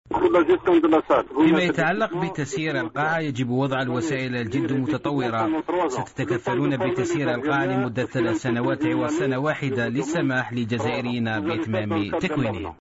الوزير الأول يتحدث عن حوصلة الاجتماع الوزاري المصغر المنعقد في قسنطينة الوزير الأول يتحدث عن المشاريع الخاصة بتظاهرة قسنطينة عاصمة الثقافة العربية الوزير الأول عبد المالك سلال مبعوث الإذاعة الجزائرية إلى قسنطينة